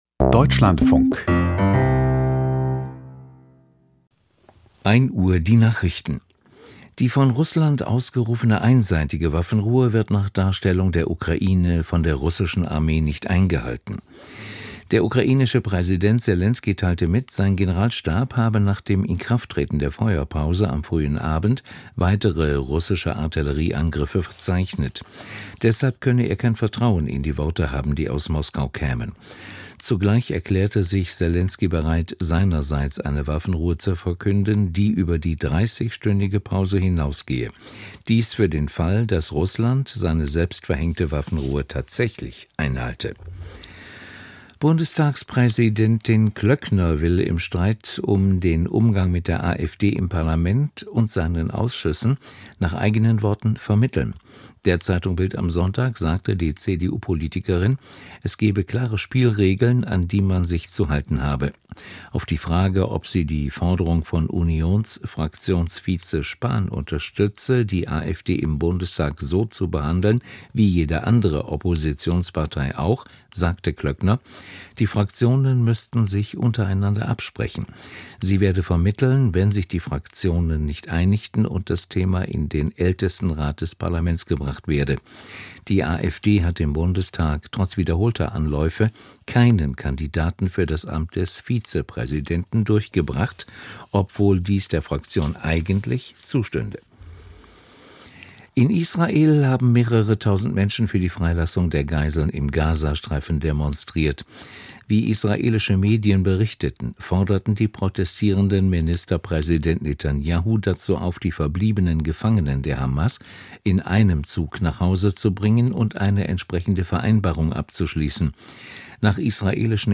Die Deutschlandfunk-Nachrichten vom 21.11.2024, 13:00 Uhr.